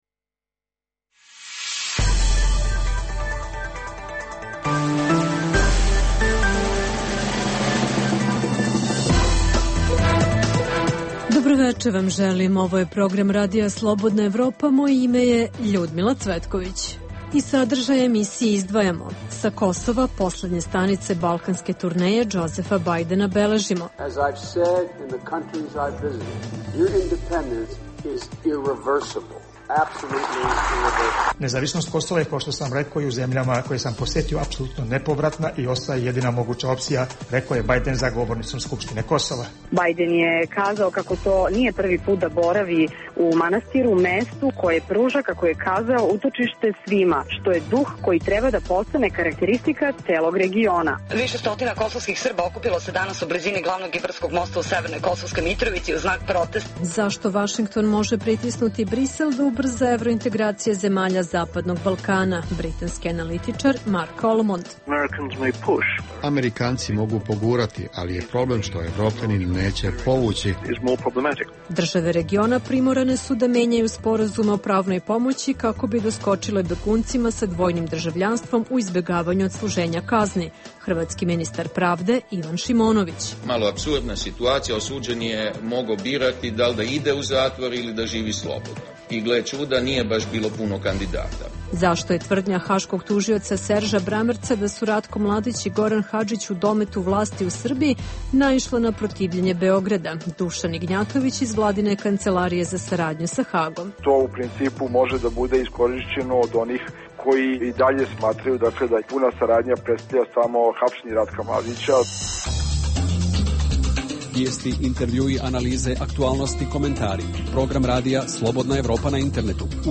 Centralna tema emisije je poseta američkog potpredsednika Džozefa Bajdena regionu. Čućete naše reportere iz Prištine, Dečana i Mitrovice